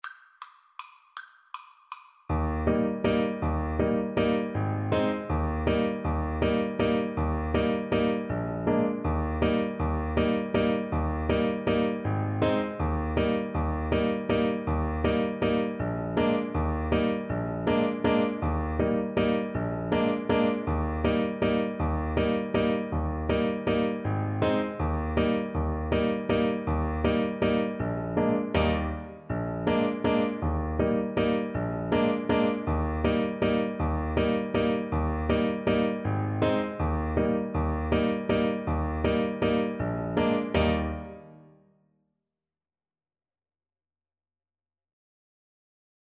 Clarinet
Eb major (Sounding Pitch) F major (Clarinet in Bb) (View more Eb major Music for Clarinet )
3/4 (View more 3/4 Music)
Molto allegro =160
F#5-Ab6
Traditional (View more Traditional Clarinet Music)